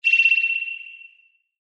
Sonar.mp3